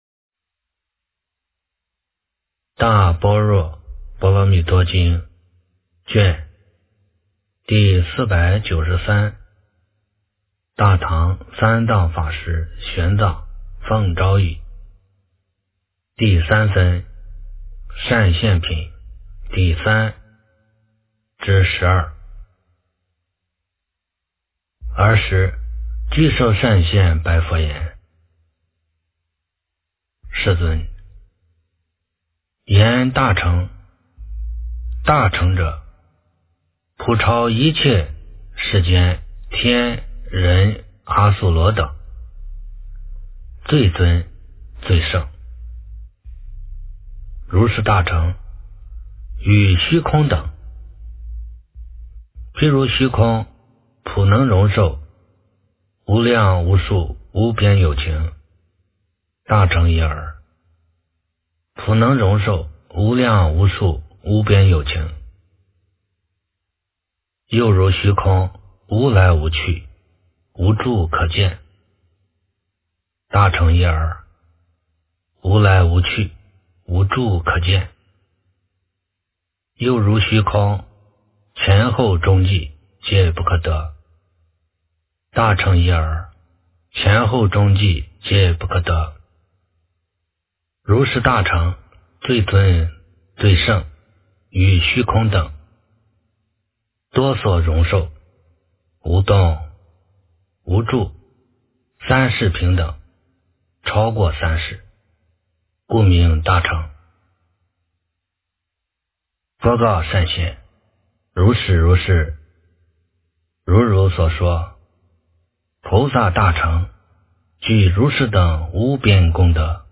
大般若波罗蜜多经第493卷 - 诵经 - 云佛论坛